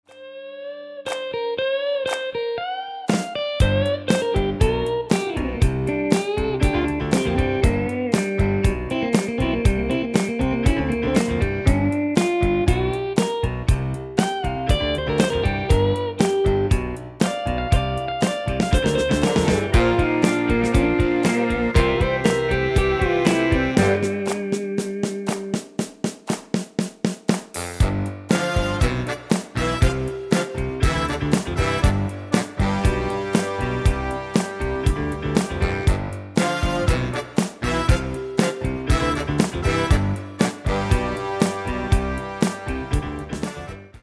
Demo/Koop midifile
Taal uitvoering: Instrumentaal
Genre: R&B / Soul / Funk
Toonsoort: G
Originele song is instrumentaal
Demo's zijn eigen opnames van onze digitale arrangementen.